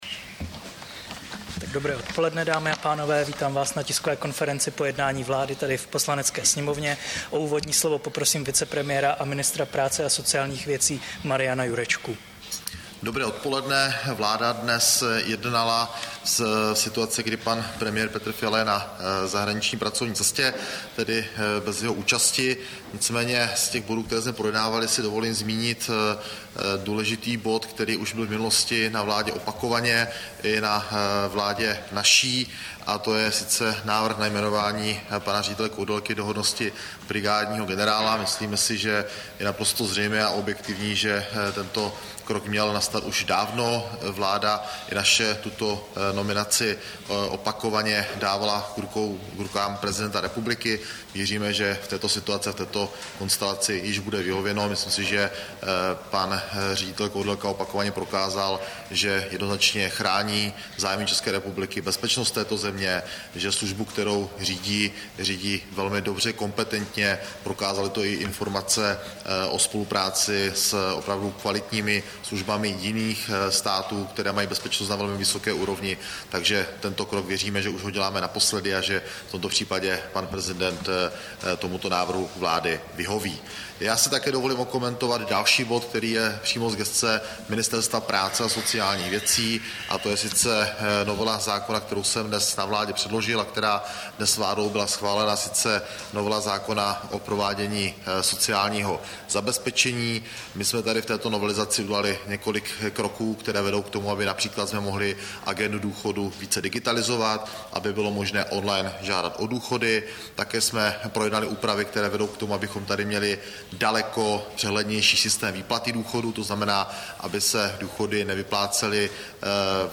Tisková konference po jednání vlády, 19. dubna 2023